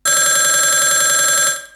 oldphone.wav